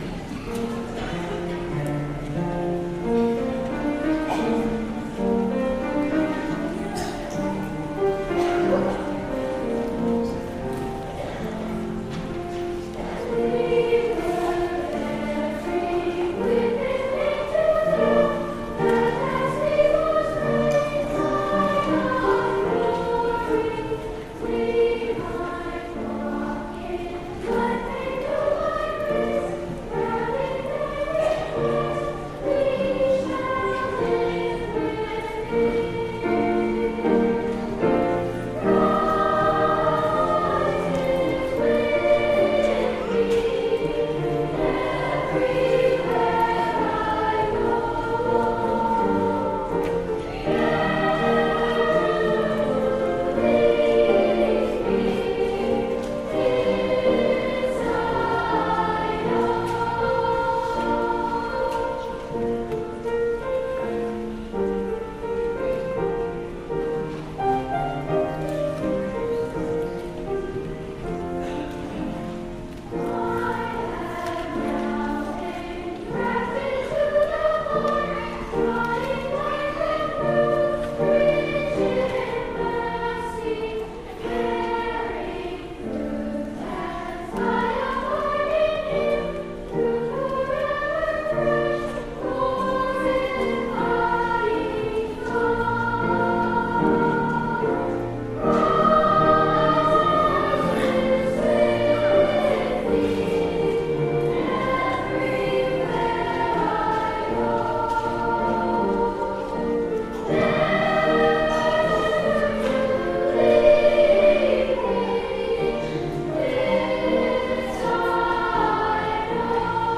St. John School Choristers (Grades 5-6)
euphonium
organ
Choral-Christ-Is-With-Me-Coleman.mp3